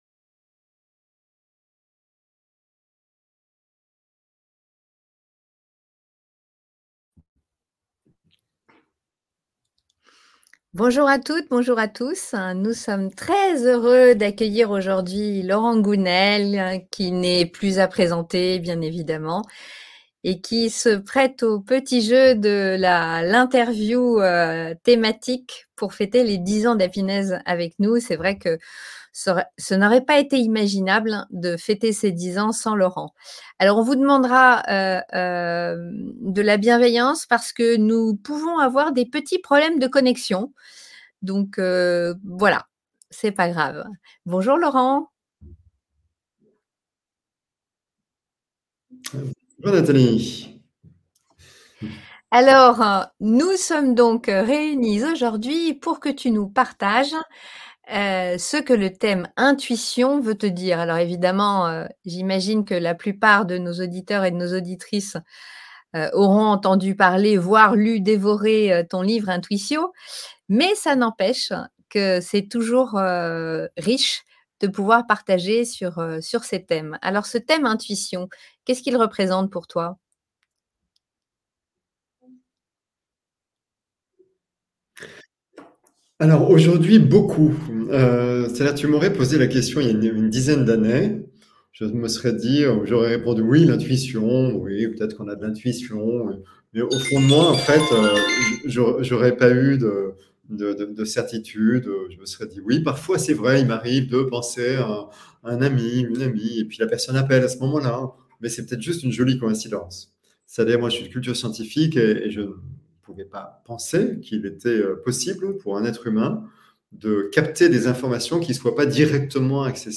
Aujourd’hui, nous retrouvons Laurent Gounelle qui a écrit des chroniques pour Happinez et dont nous soutenons le travail depuis des années.